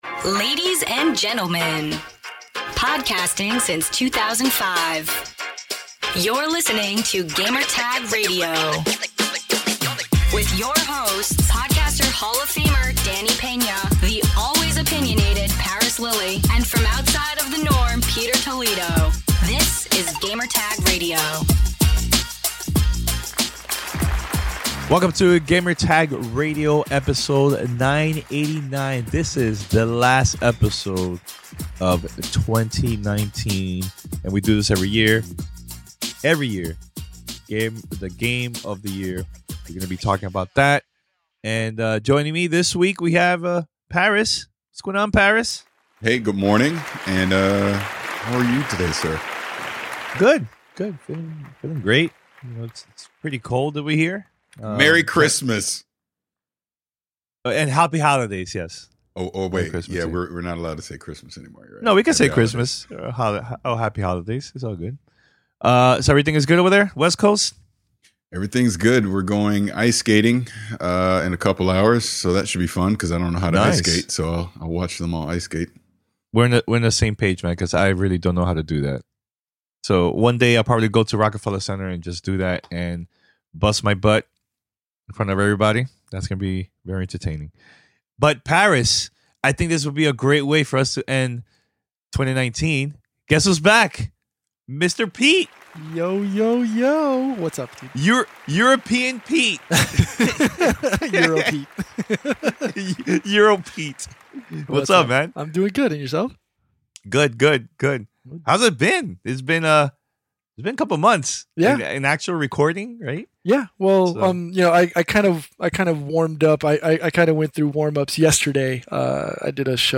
Game of the year 2019 roundtable discussion, predictions and more.